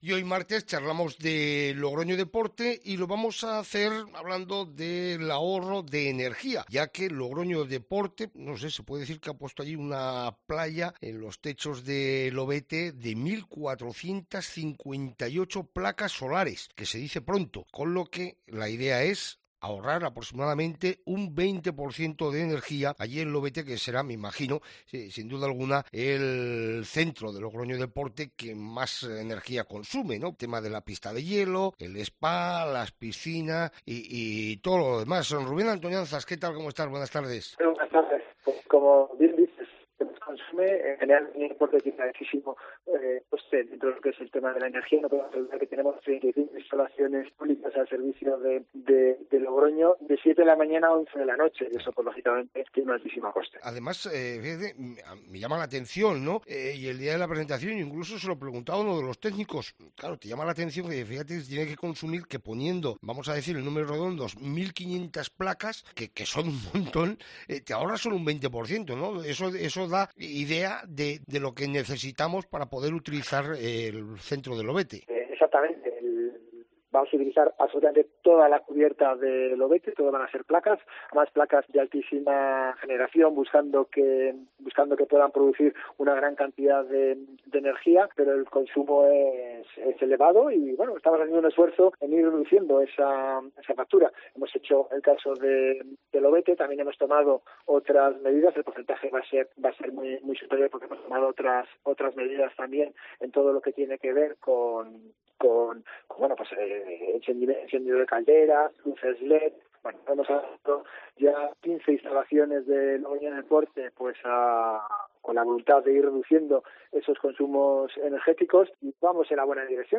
El concejal de Deportes, Rubén Antoñanzas, destaca en COPE la apuesta de Logroño Deporte por las energías alternativas: